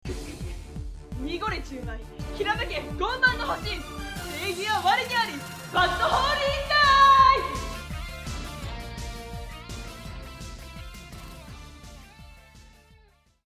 魔法少女まじかる☆ろねりんの必殺技ボイス　らしいですよ212KB14/02/24(Mon),09:49:18audio/mpegまじかるろねりん.mp3